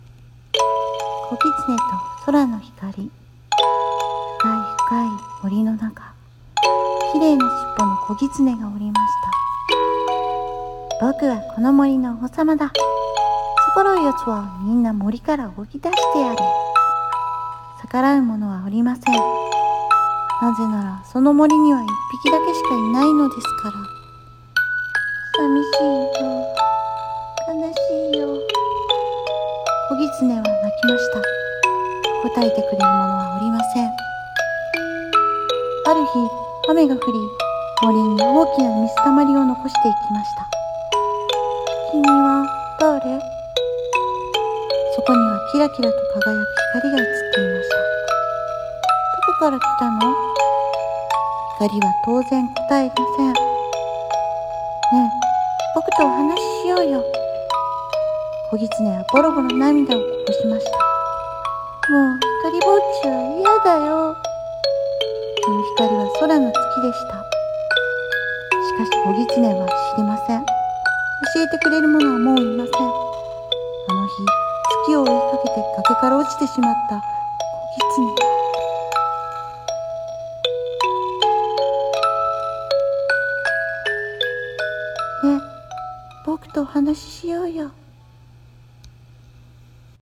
】【朗読台本】こぎつねとそらの光 読み手様：。